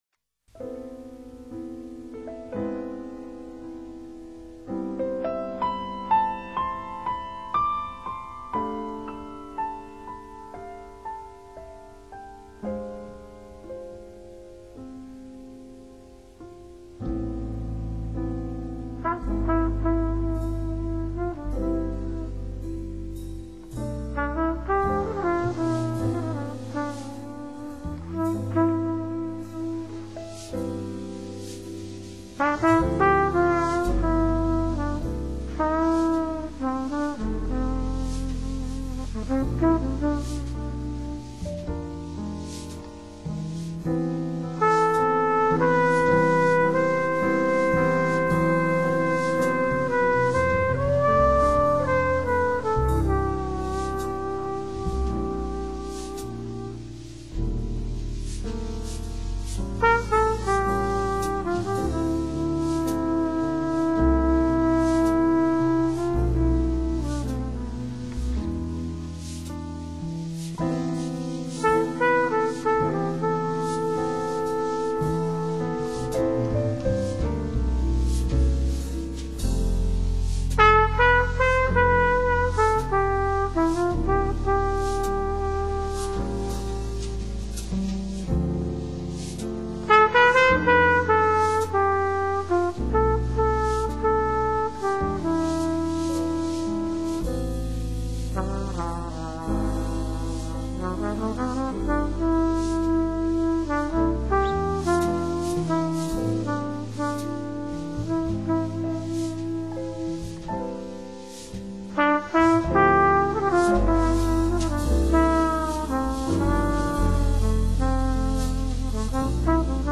★爵士情歌王子后期录音精华
★浪漫歌声中透著一点沧桑